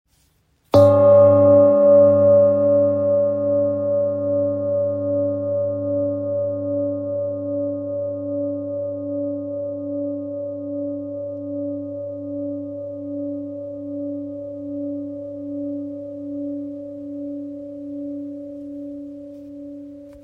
Authentic Ancient Seven Metal Bowl – 20.5cm
The rich, ancient tones reach into the soul, calming the mind and unlocking inner peace.
Its authenticity is evident in every detail, from its textured surface to its resonant sound, which carries the wisdom of the ages.
Ancient-bowl-mullet.mp3